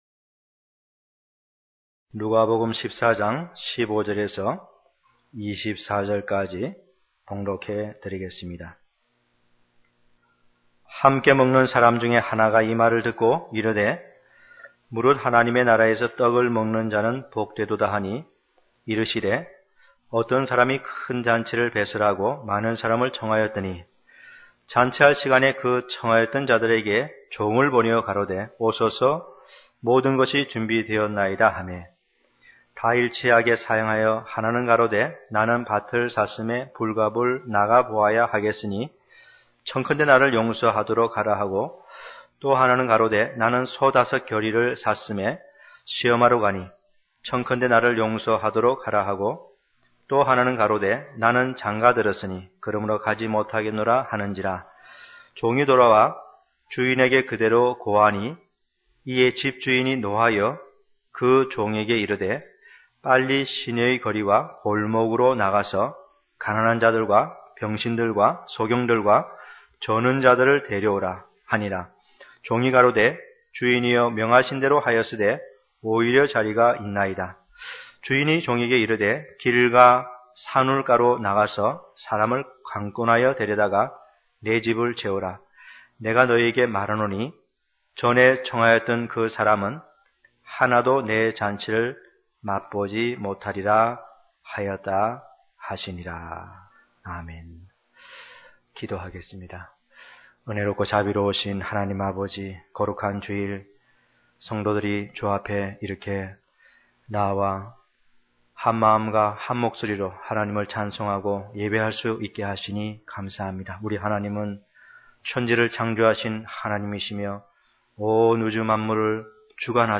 큰 잔치의 비유(2) > 시리즈설교 | 진리교회